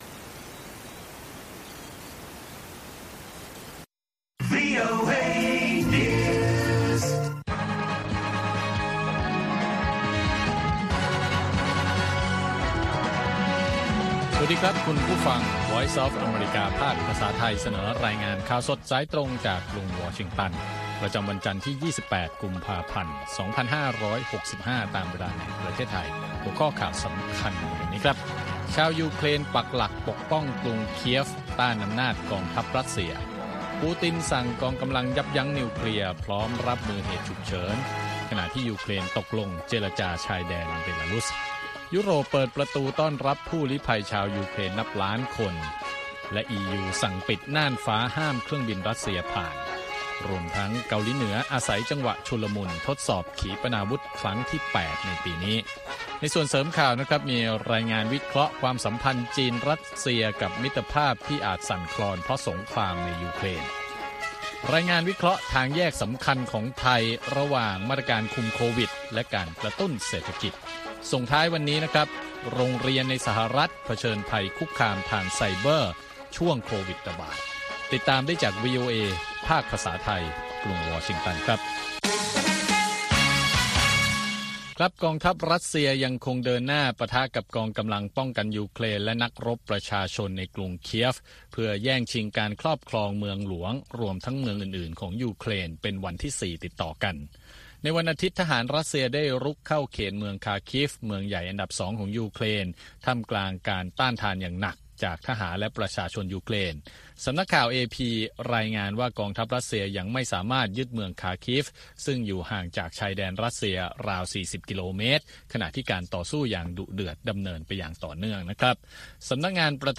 ข่าวสดสายตรงจากวีโอเอ ภาคภาษาไทย 8:30–9:00 น. ประจำวันจันทร์ที่ 28 กุมภาพันธ์ 2565 ตามเวลาในประเทศไทย